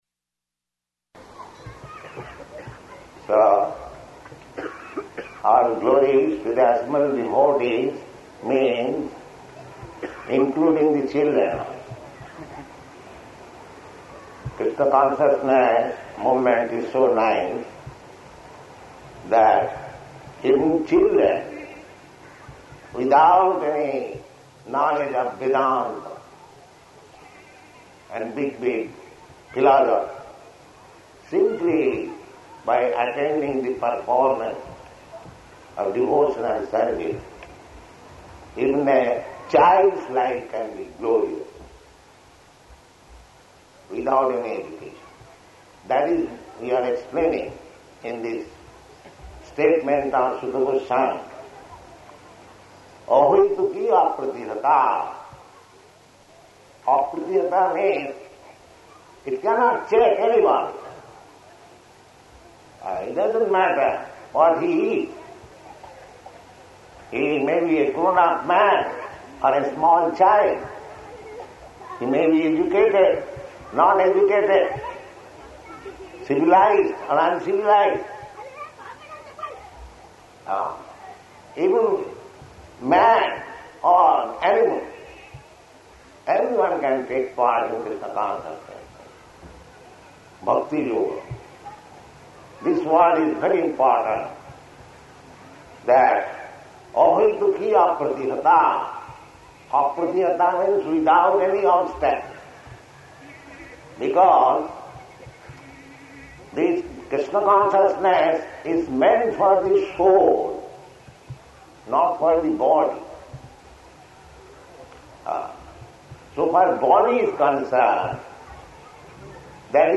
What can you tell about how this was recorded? Location: Hyderabad